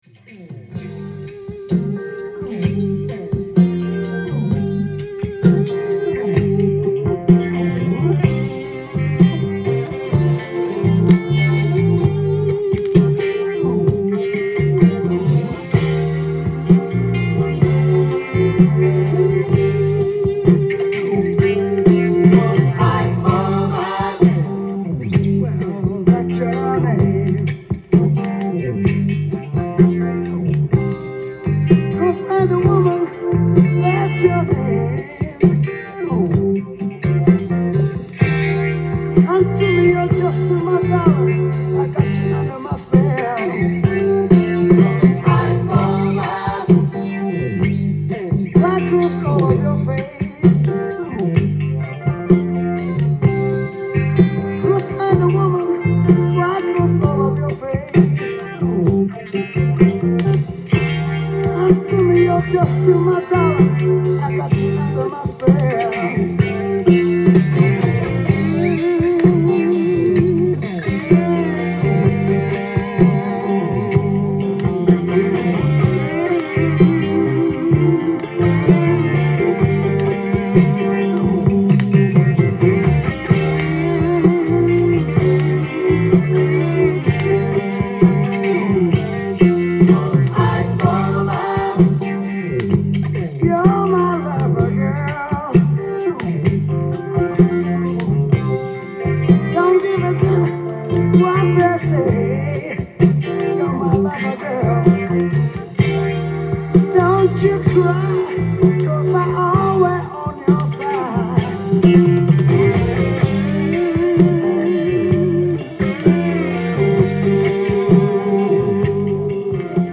ジャンル：J-BLUES
店頭で録音した音源の為、多少の外部音や音質の悪さはございますが、サンプルとしてご視聴ください。